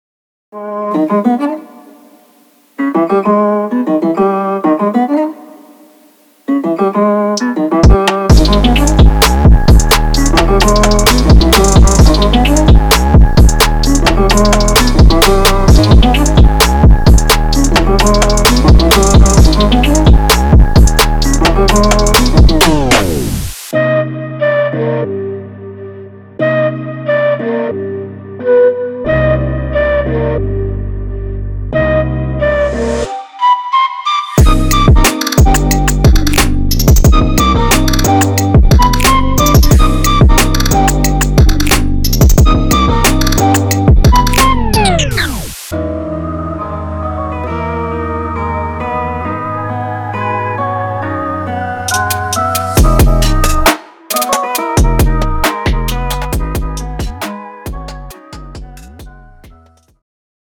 inspired by the dark atmosphere and neo aesthetics.
It is the final result of many sessions in the studio.